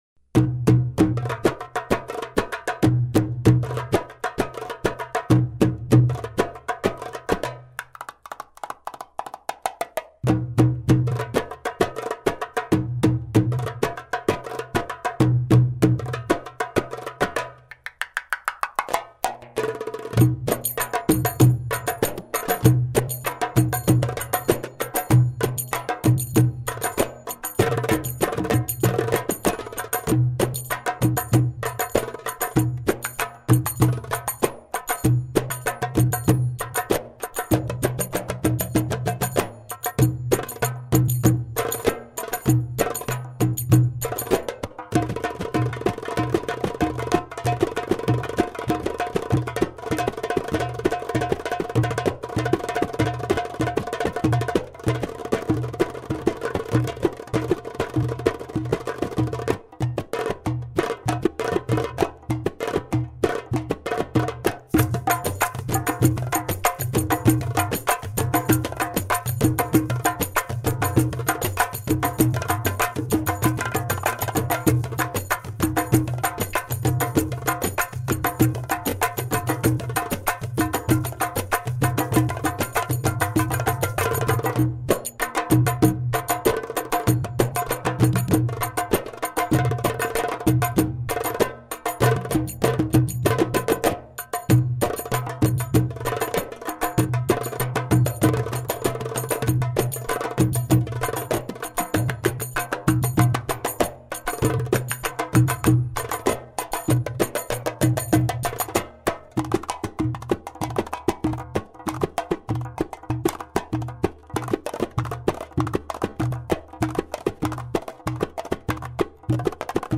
Барабаны (инструментальная)